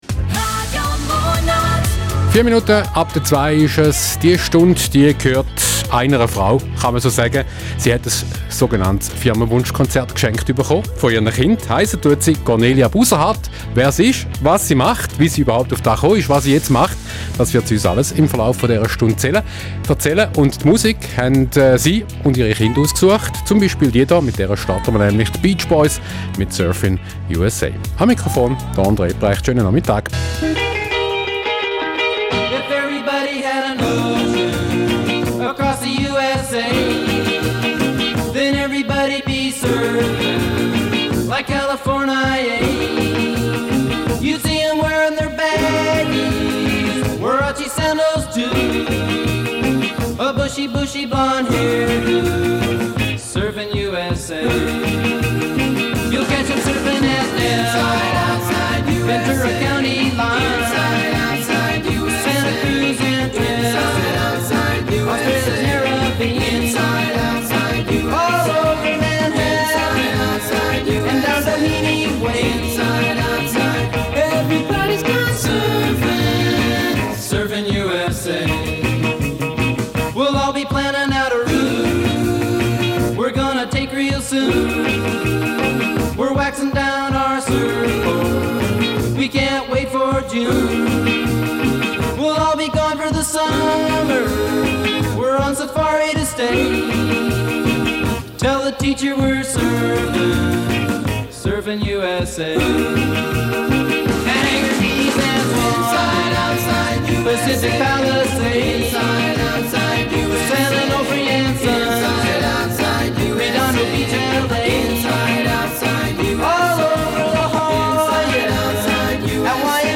Medienecho Radio Munot – Interview